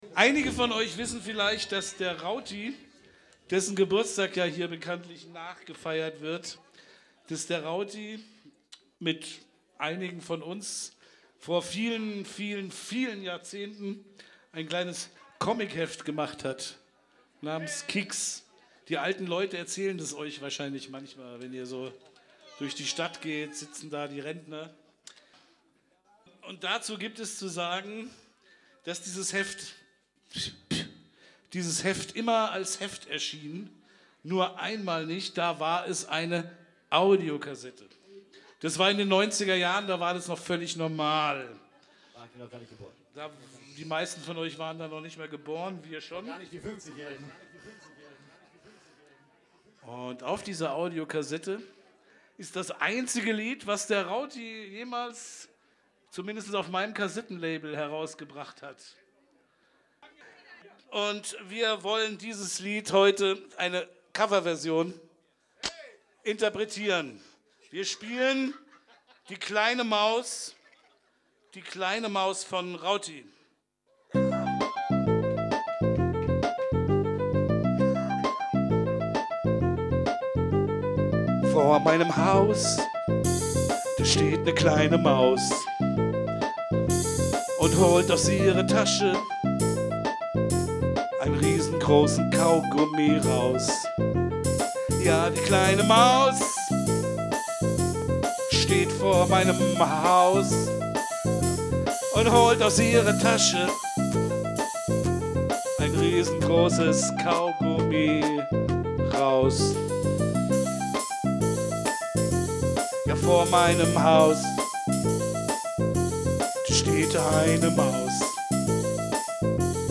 Hier ein schöner Live Mitschnitt aus der Hafenkneipe.